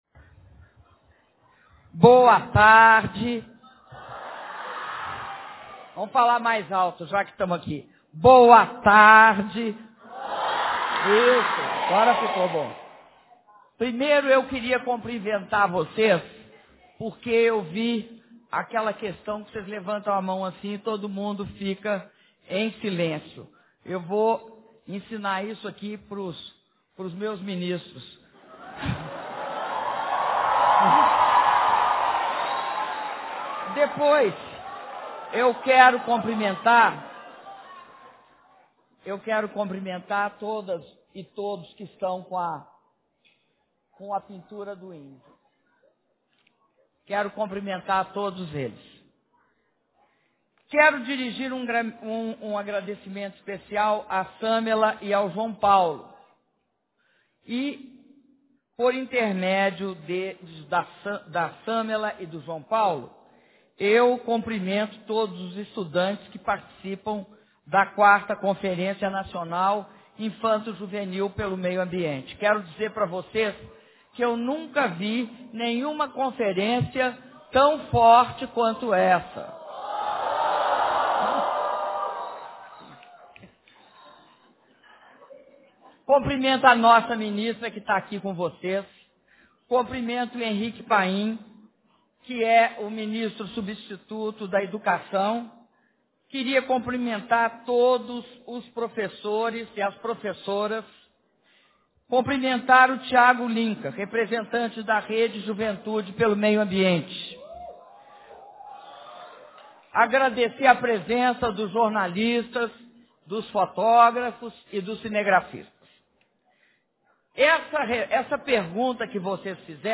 Áudio do discurso da Presidenta da República, Dilma Rousseff, durante encontro com participantes da IV Conferência Nacional Infantojuvenil pelo Meio Ambiente - IV CNIJMA - Brasília/DF